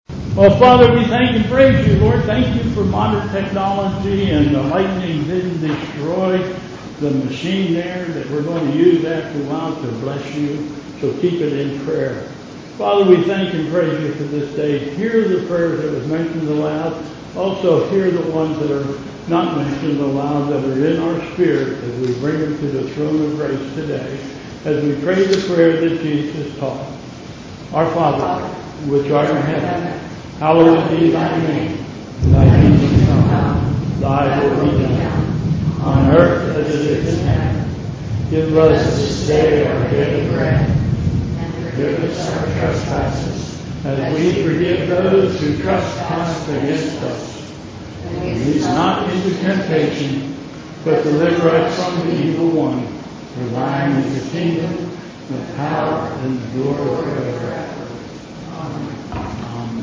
Bethel Church Service
Pastoral Prayer and the Lord's Prayer